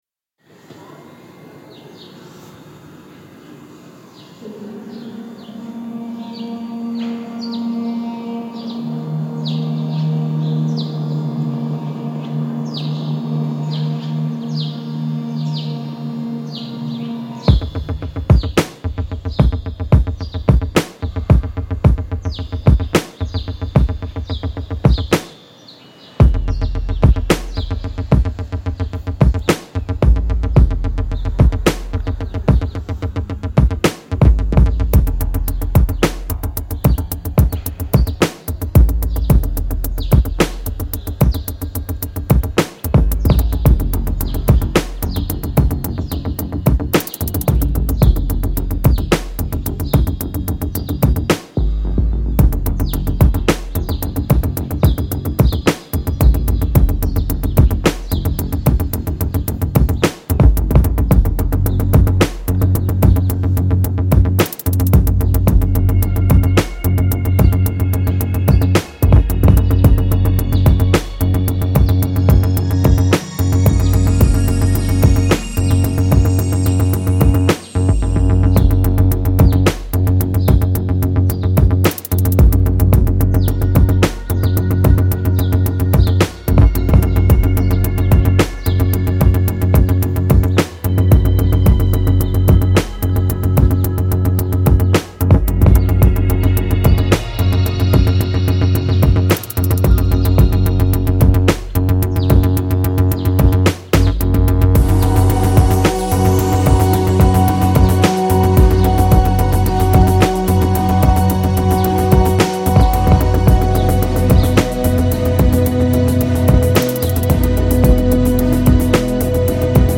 Spring in Tehran reimagined